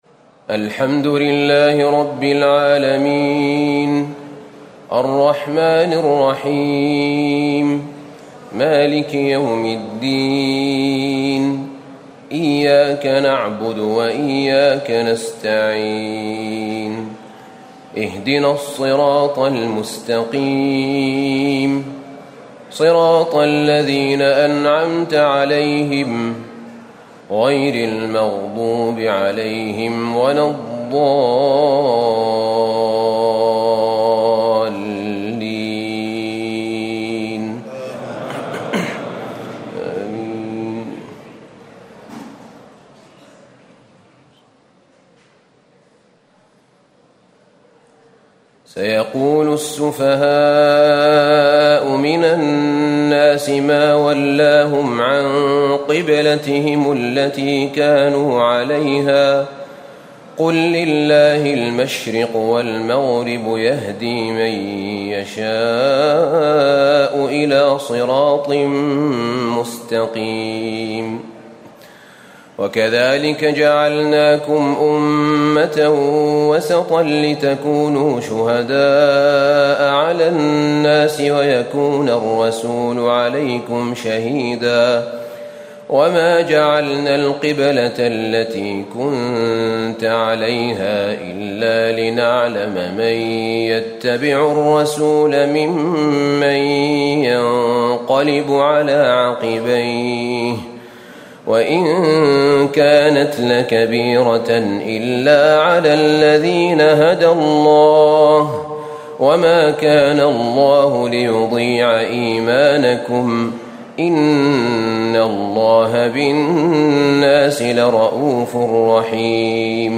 تهجد ليلة 22 رمضان 1437هـ من سورة البقرة (142-218) Tahajjud 22 st night Ramadan 1437H from Surah Al-Baqara > تراويح الحرم النبوي عام 1437 🕌 > التراويح - تلاوات الحرمين